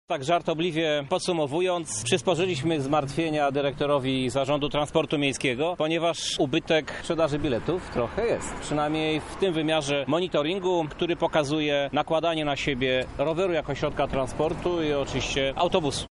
– tłumaczy Krzysztof Żuk, prezydent Lublina.